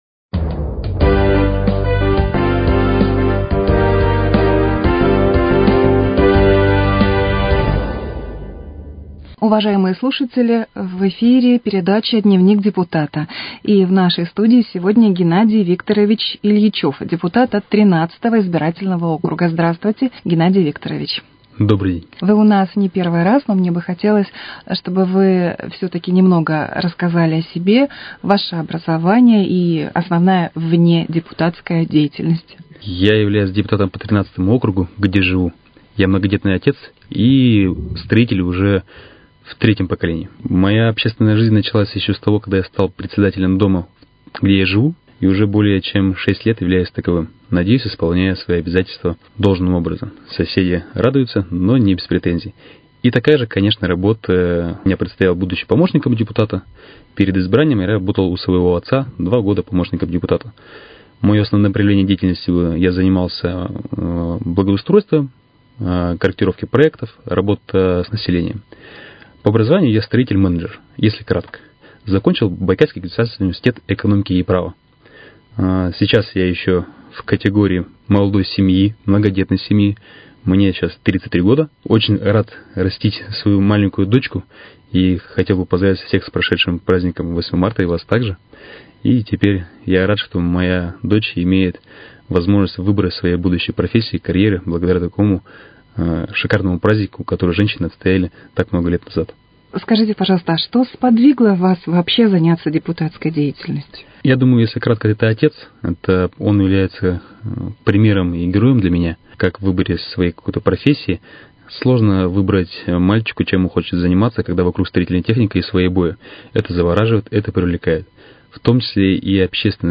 В передаче принимает участие депутат Думы г.Иркутска по округу № 13 Геннадий Ильичёв.